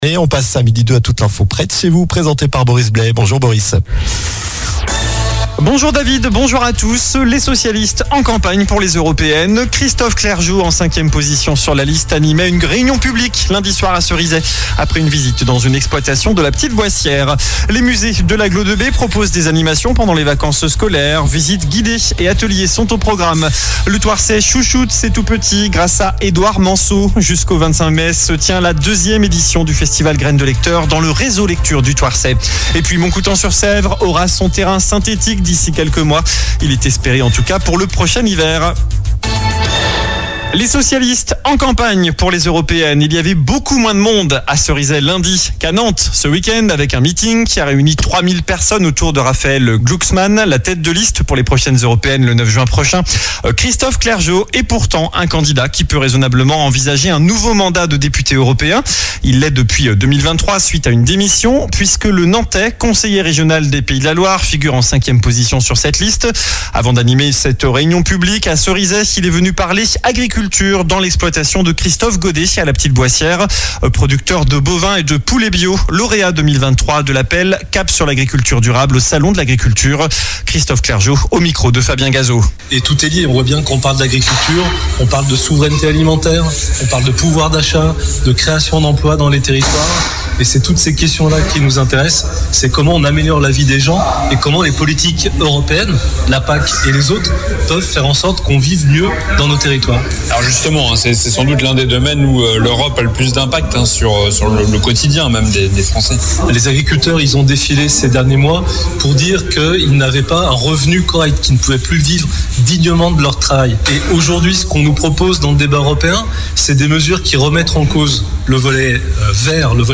Journal du mercredi 17 avril (midi)